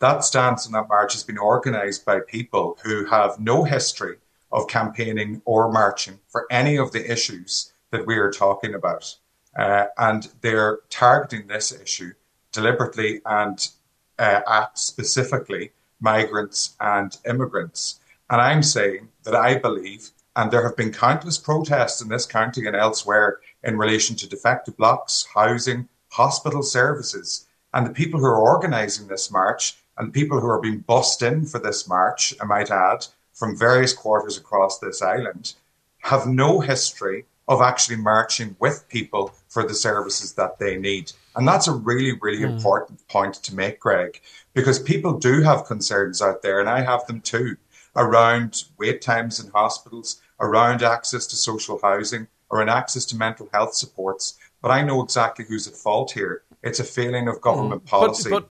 Cllr Declan Meehan says the Government is to be blamed for the lack of resources rather than immigration: